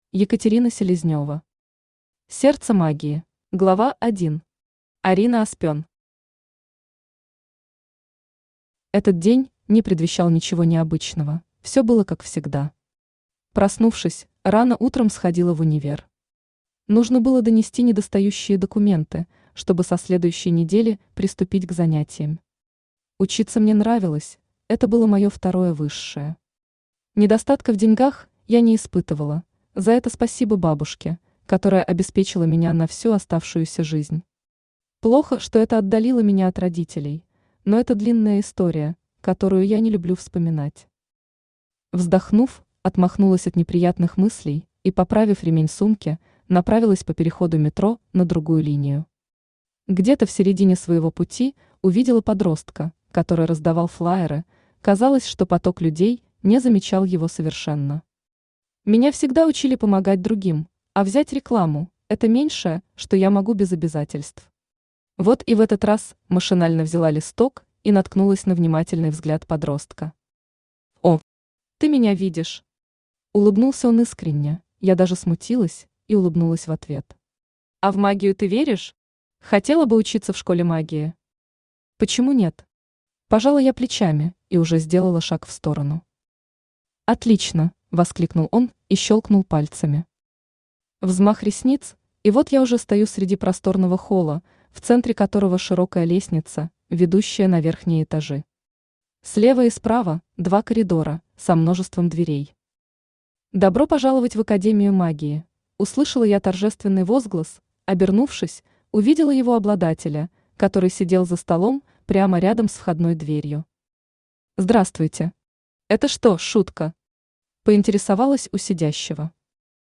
Aудиокнига Сердце магии Автор Екатерина Юрьевна Селезнёва Читает аудиокнигу Авточтец ЛитРес.